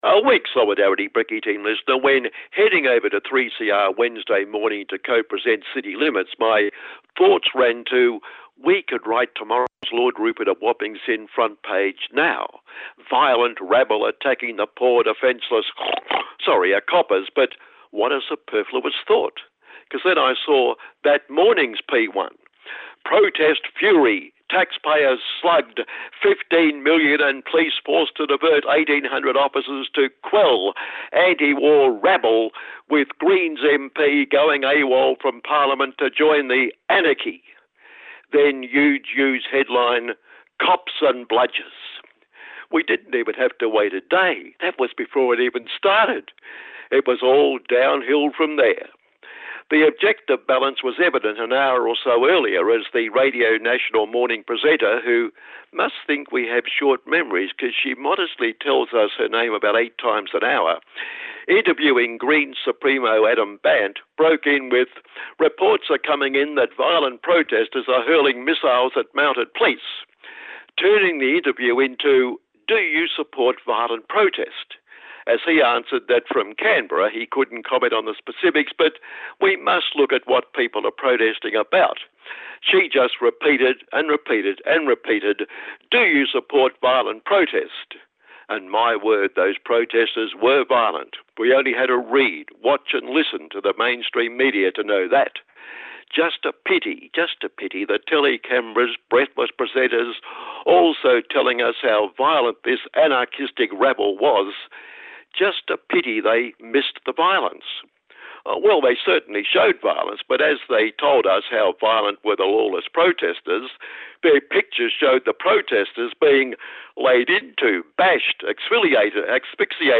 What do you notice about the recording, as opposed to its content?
Vox pop from Wednesday 11th September when the most aggressive police actions occurred.